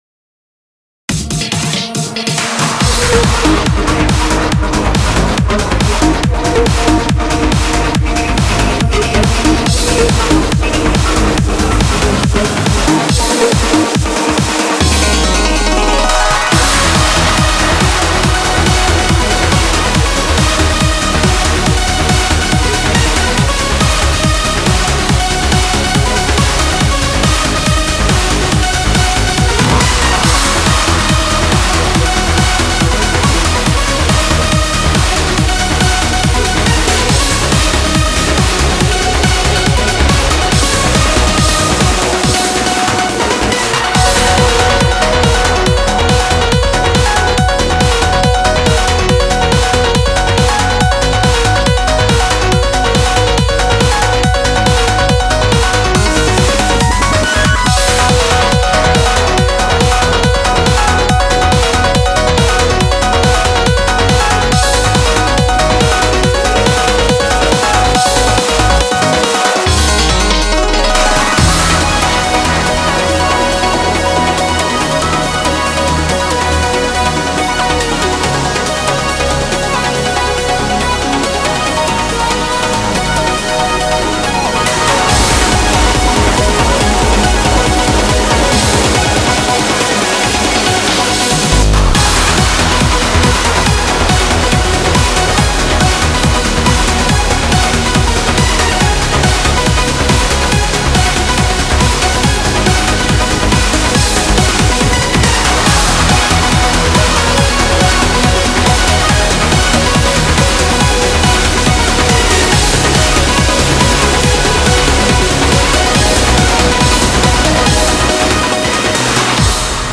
BPM140
Audio QualityPerfect (High Quality)
Comments[NOSTALGIC TRANCE]